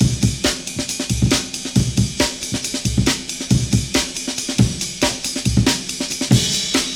amen.wav - for use with the bon-amen breakbeat sampler. 808kit.tar.bz2 - to be used in tr808
amen.wav